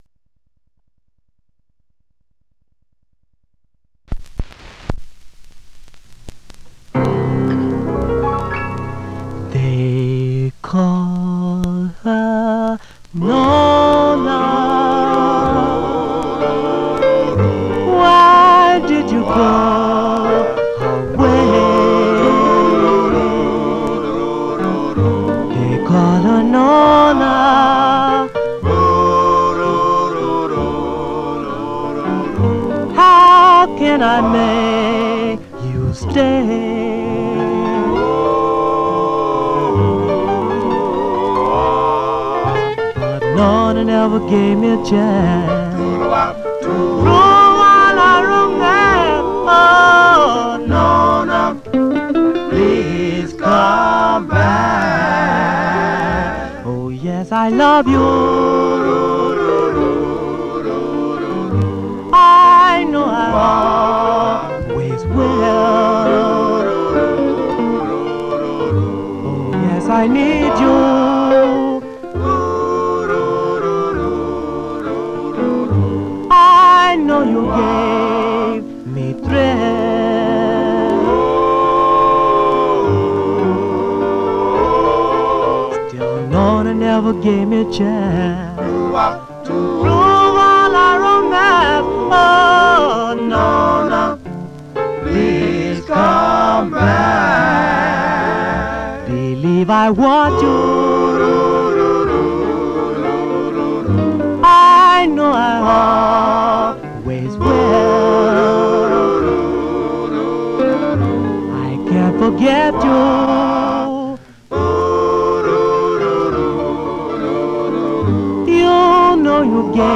Mono
Vocal Group